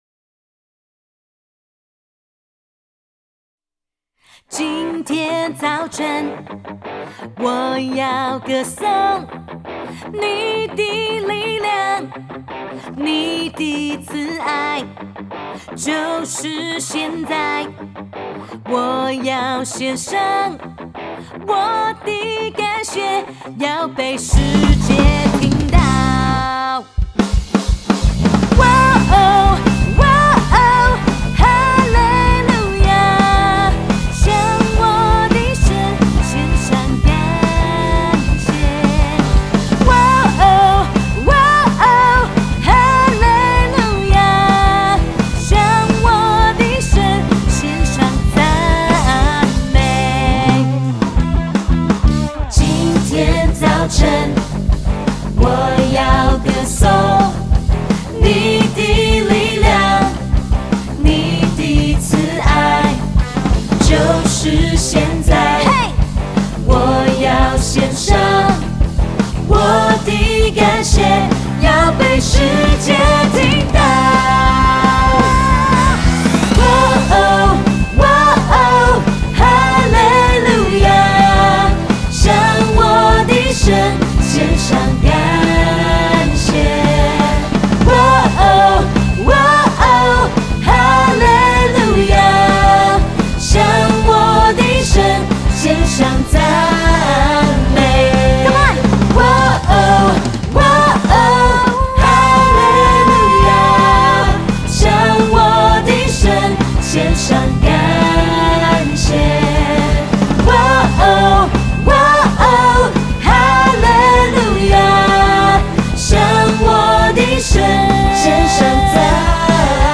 以原声（Acoustic）的方式呈现